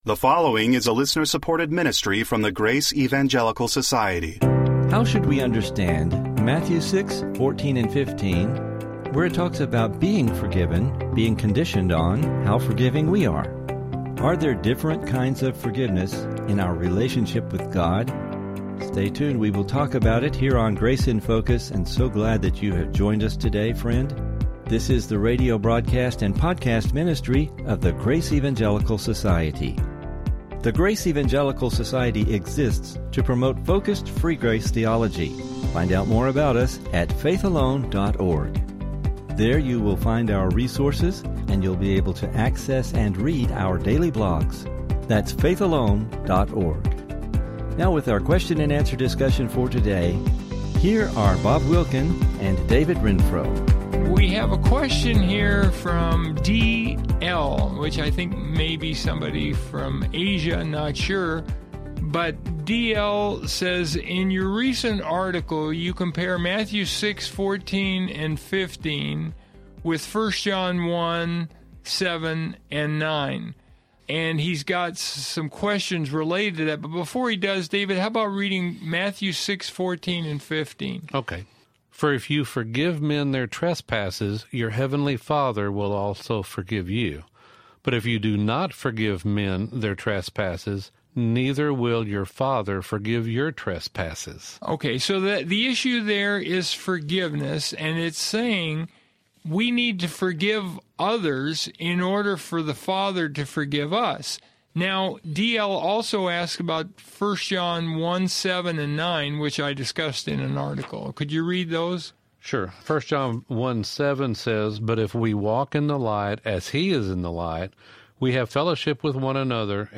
How should we understand Matthew 6:14-15, forgiveness and eternal security? If we lose fellowship with God do we lose eternal salvation? Please listen for an interesting Biblical discussion regarding this topic!